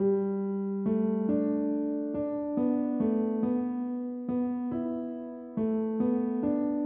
摇篮曲钢琴
Tag: 140 bpm Rap Loops Piano Loops 1.15 MB wav Key : D